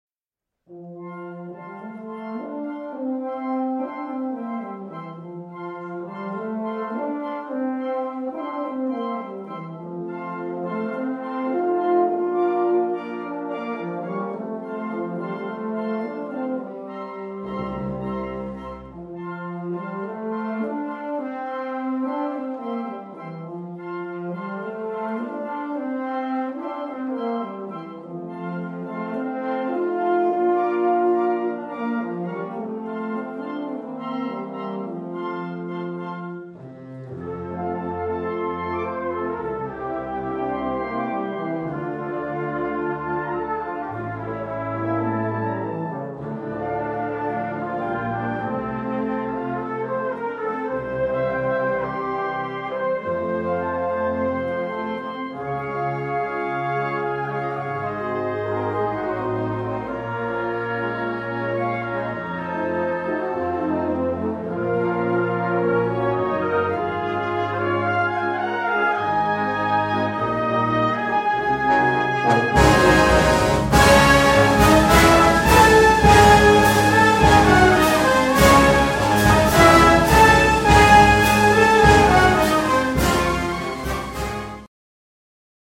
symphonic and military marches and scherzos